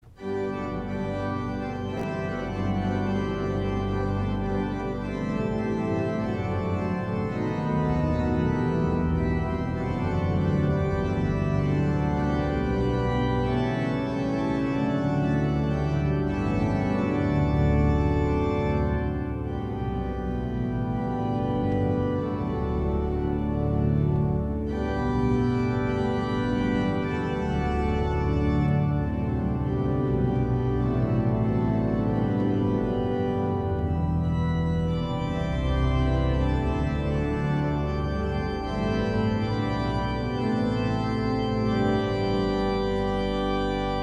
R11_Mix Major.mp3